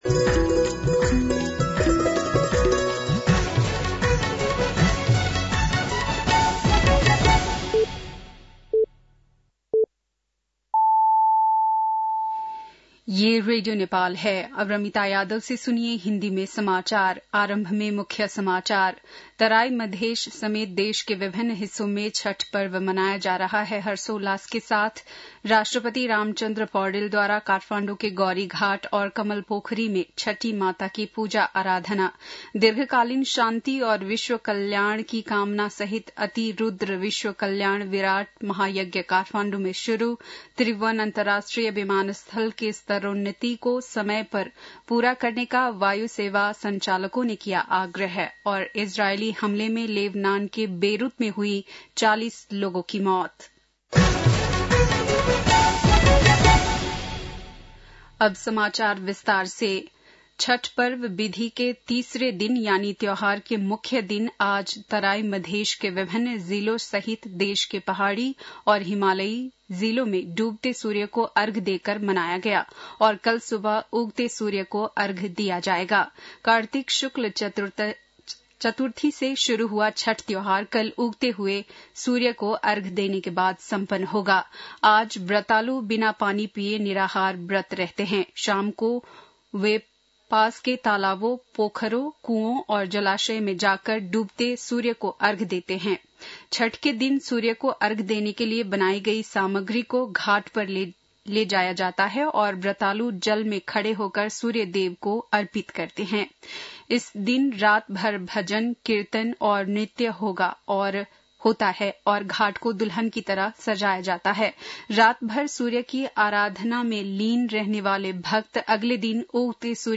बेलुकी १० बजेको हिन्दी समाचार : २३ कार्तिक , २०८१